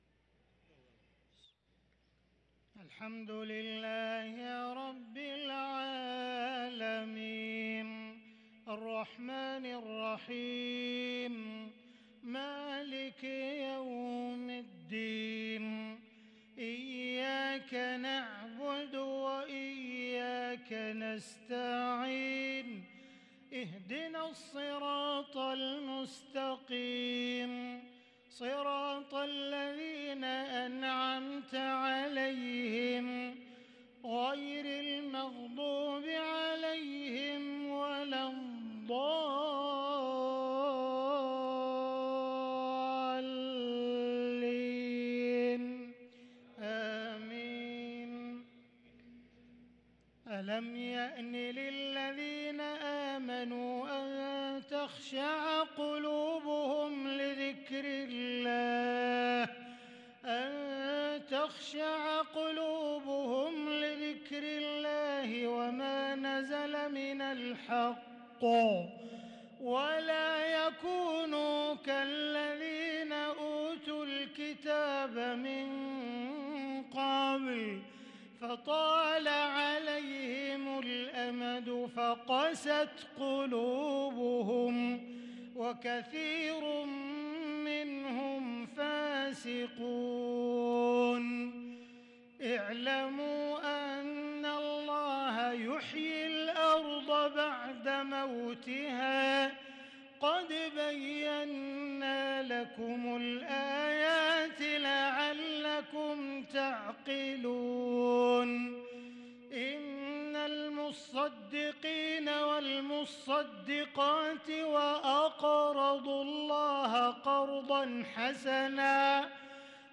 صلاة العشاء للقارئ عبدالرحمن السديس 22 صفر 1444 هـ